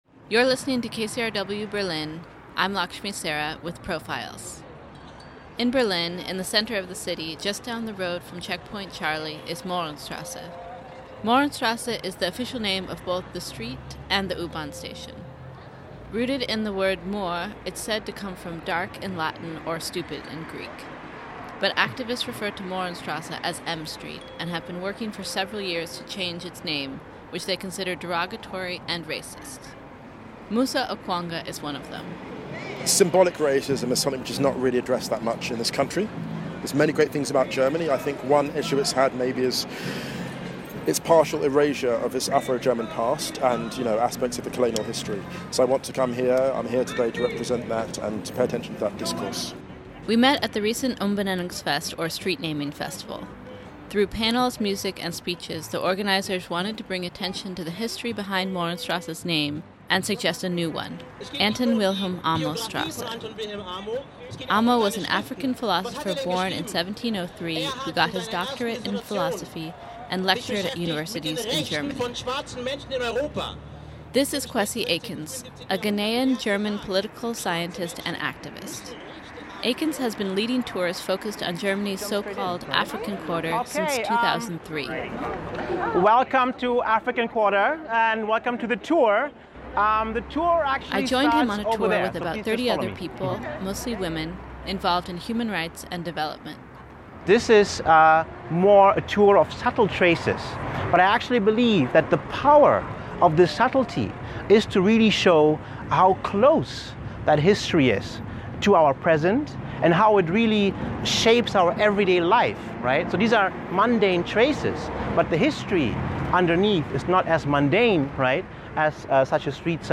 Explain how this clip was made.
Produced for KCRW Berlin.